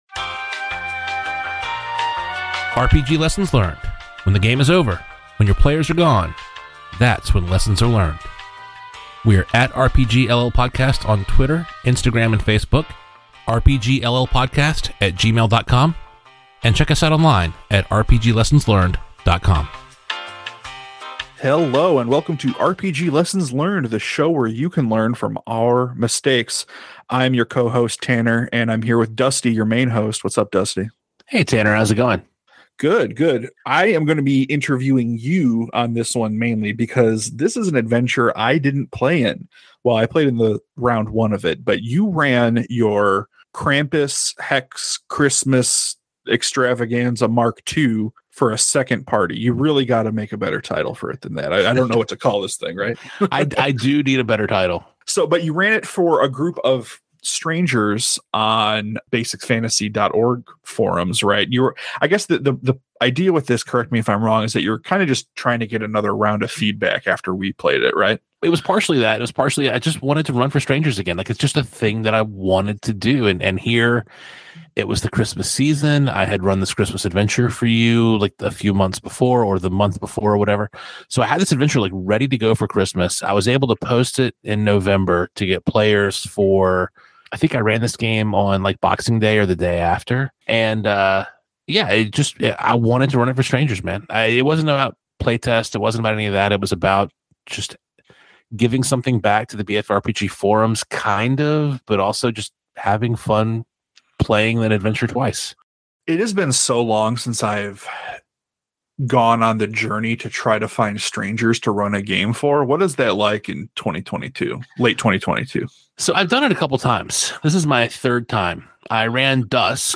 runs his original hex-based Christmas dungeon for strangers online!